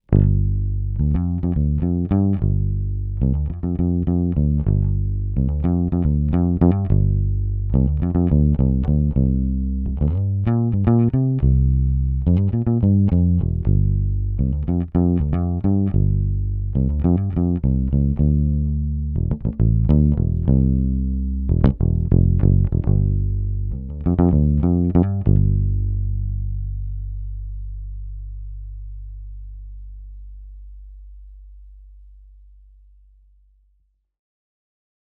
Pure, Sweet, and Authentic Tone.
We get every part of the Epic 1959 pickups specially manufactured, only for us, from an actual 1959 pickup.  1959 Epic pickups have a huge chunky low end, often not associated with current magnets, as current magnets have a nasty Mid Range presence that also pairs with the chunkiness.   The mids on the 1959 set are very solid, not overbearing but are shifted to the lower mid frequencies not the upper dark mids.   The treble is very velvety but still holds it's top end clarity.
All in all, the 1959 precision bass pickup is huge and fat with just the perfect amount of bite.